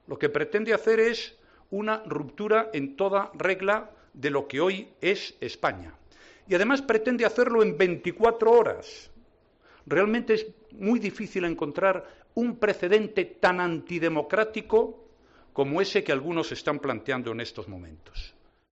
Rajoy ha emplazado a Puigdemont a que acuda al Congreso en su comparecencia ante los periodistas tras la reunión del Comité Ejecutivo Nacional del PP y después de conocer el borrador de esa ley de ruptura.